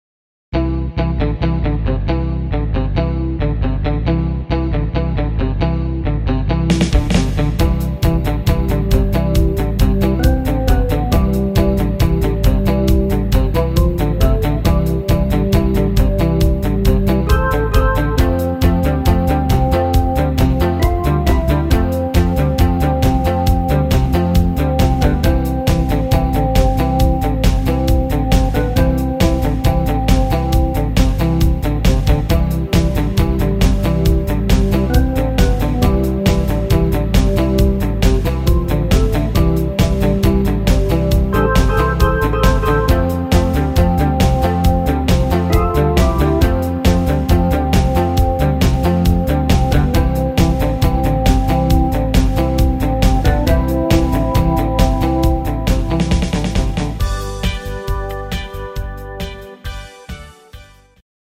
instr. Orgel